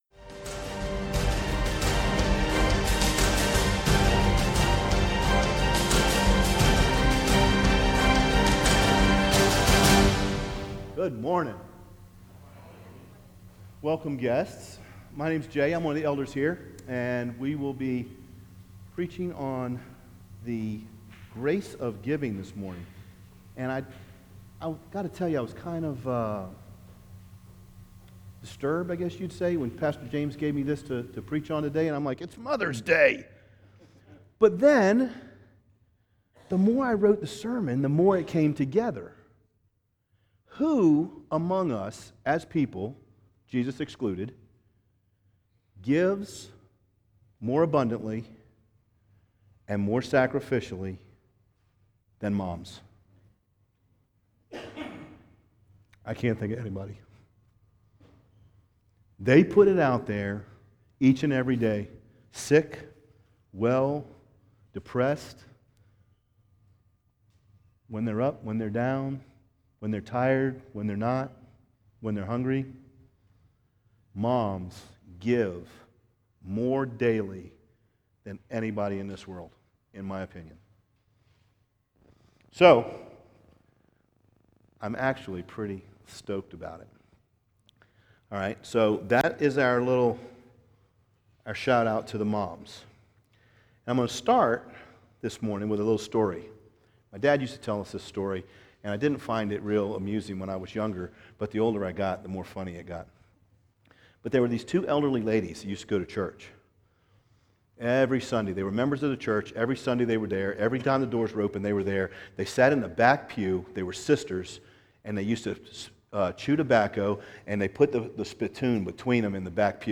What we are going to preach on today is the “The Grace of Giving”.